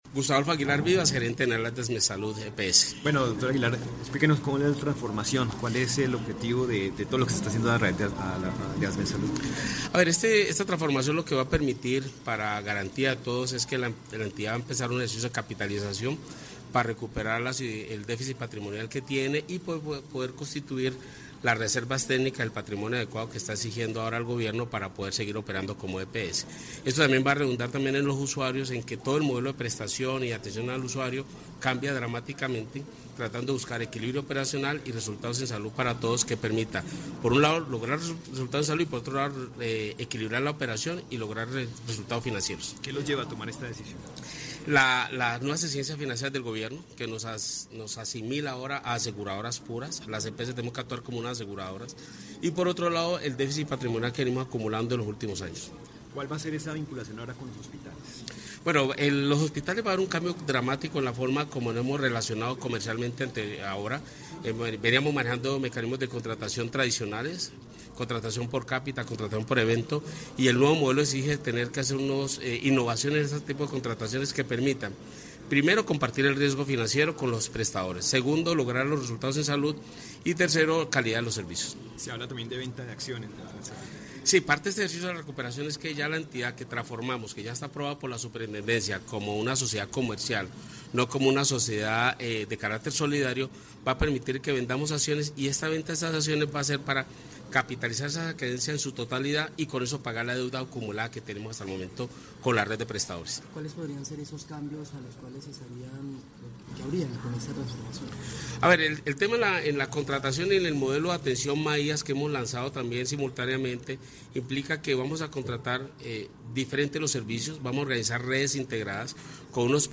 En rueda de prensa, y acompañado de sus directivos, manifestó que frente a los cambios que se avecinan, se entra a una etapa de perfeccionamiento del proceso de escisión lo cual permitiría iniciar operación como ASMET SALUD EPS SAS a partir del 1 de abril del año en curso.